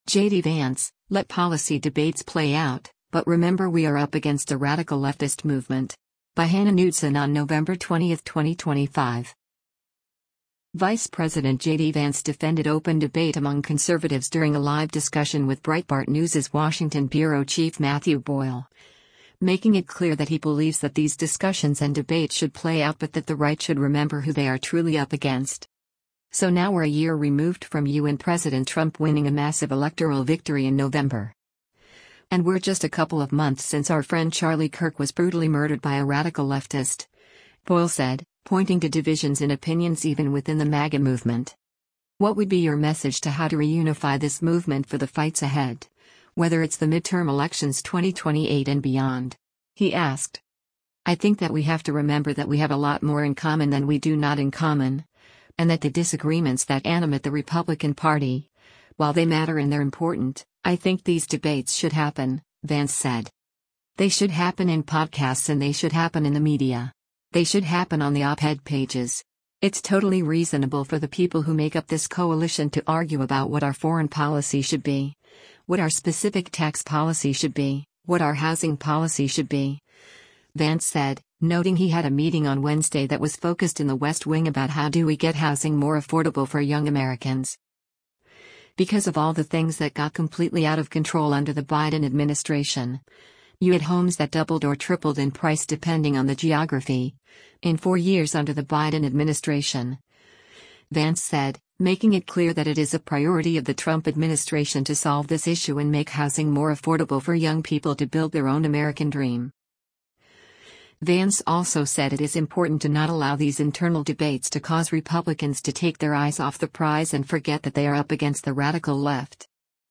JD Vance - Breitbart News Event